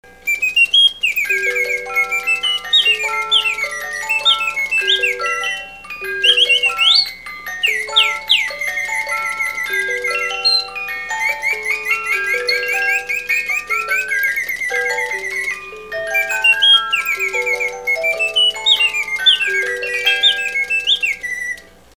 Bird&Box.mp3